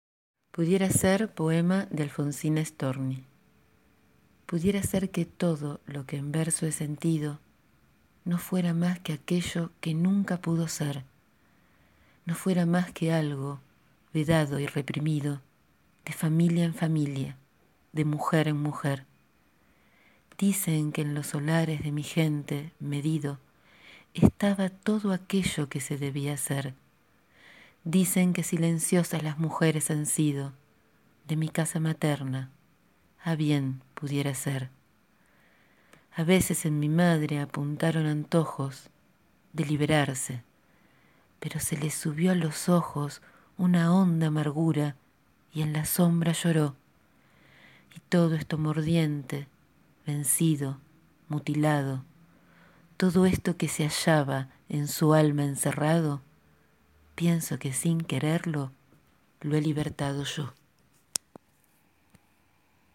Alfonsina Storni (1892-1938), autora del poema que hoy les leo, fue una escritora, poeta y periodista que dedicó su obra a luchar contra las discriminaciones y desigualdades que padecen las mujeres.